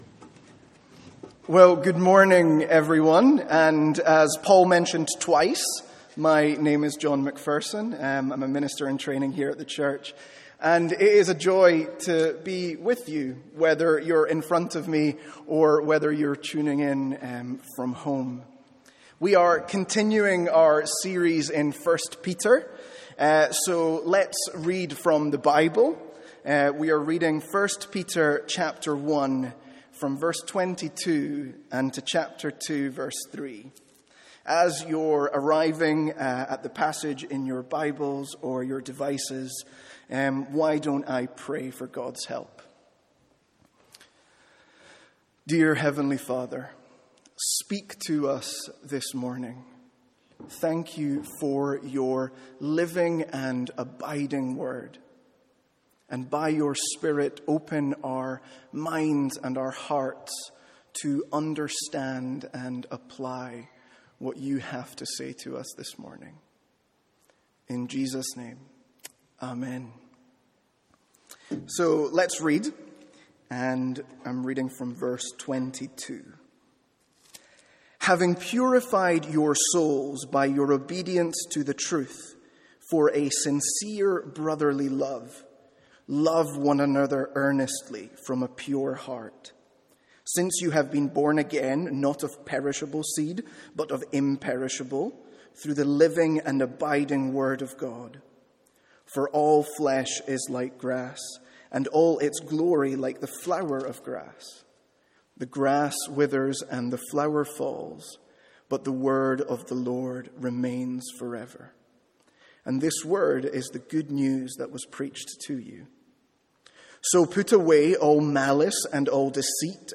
From our morning series in 1 Peter.